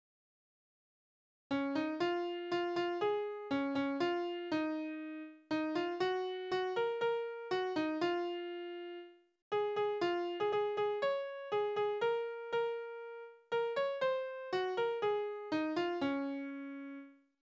Naturlieder
Tonart: Des-Dur
Taktart: 4/4
Tonumfang: Oktave
Besetzung: vokal